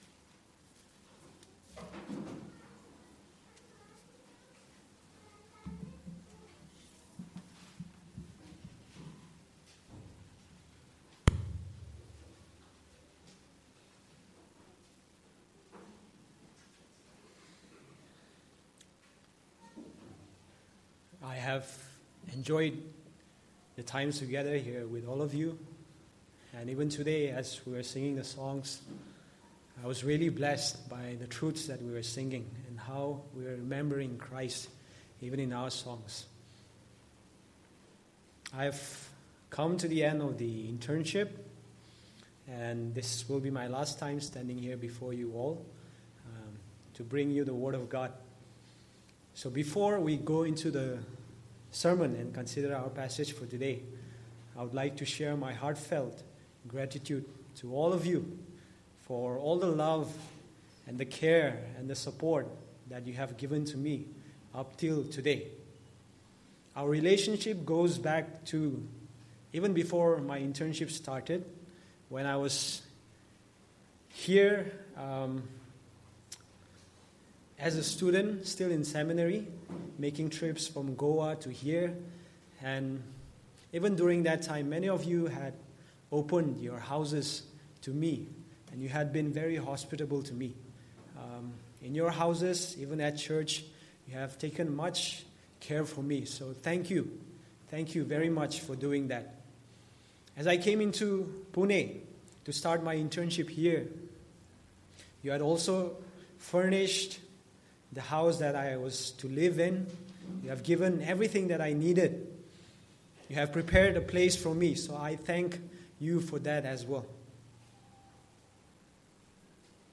Passage: Galatians 4:21-5:1 Service Type: Sunday Morning